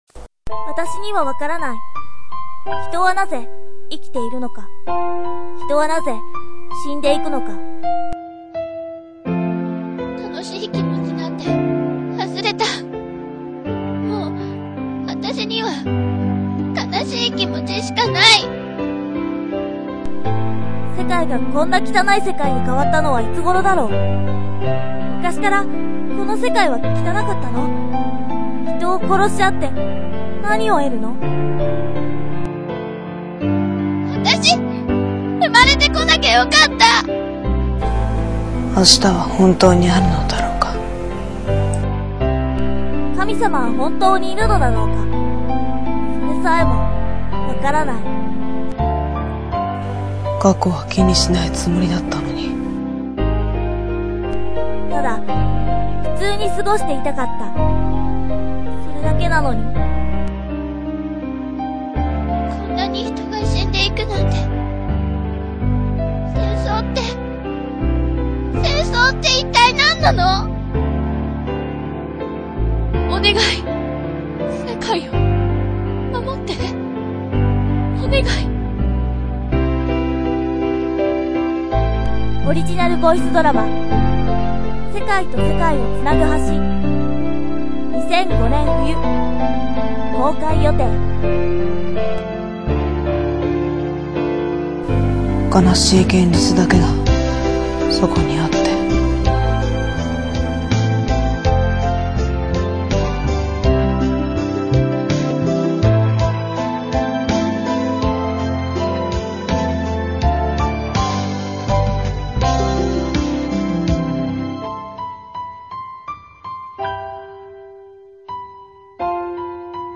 ●過去作品ＣＭ予告ストーリー　→